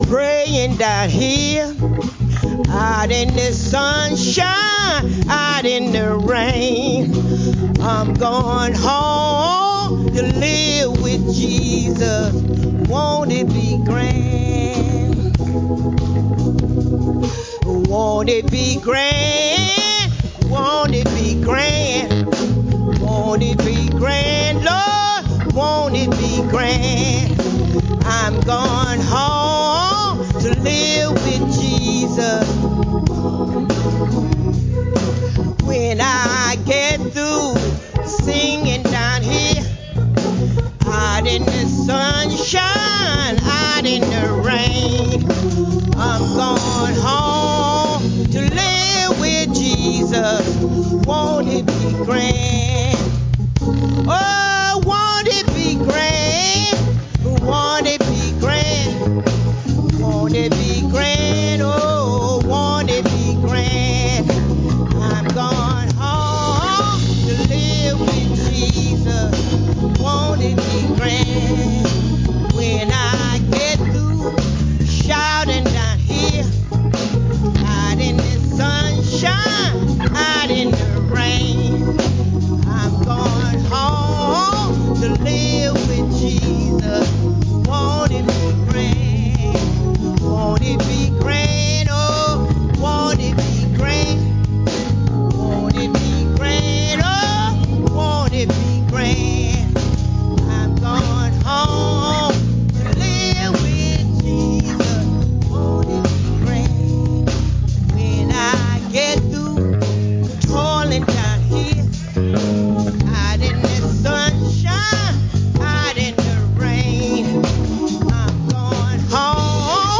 10:45 A.M. Service: Something to Sing About